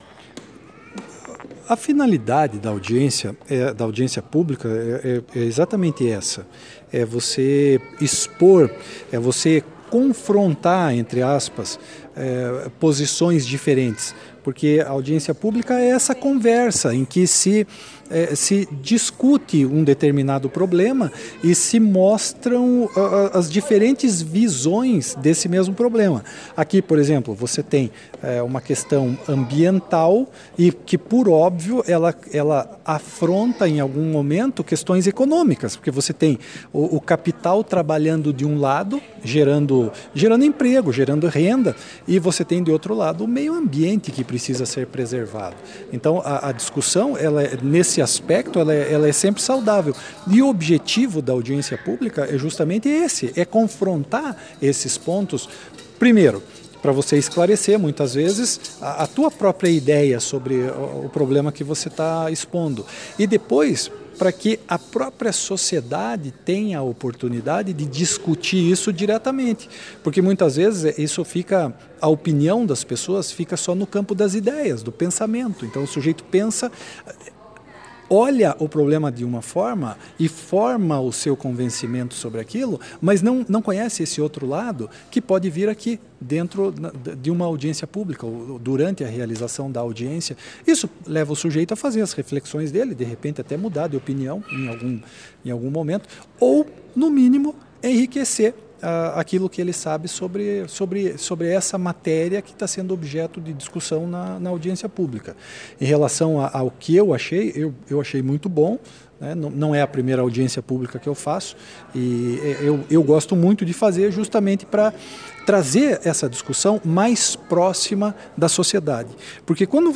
A 2ª Promotoria de Justiça de Andirá realizou dia 3 de dezembro, no cineteatro São Carlos, a audiência pública que visou discutir a queima da palha da cana-de-açúcar nas propriedades rurais do município e seus efeitos ambientais.
Ouça a explanação do promotor de Justiça Dr. Antônio Basso Filho:
Dr-Antônio-Basso-Filho_Promotor-de-Andirá.mp3